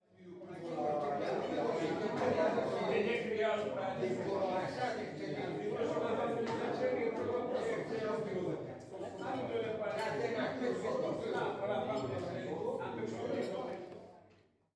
17/01/2016 20:00 Au fond de la taverne, des hommes jouent aux cartes.